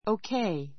OK 1 O.K. 小 A1 oukéi オウ ケ イ 形容詞 副詞 間投詞 話 よろしい （all right） , 問題ない, オーケーだ; さて Everything is OK.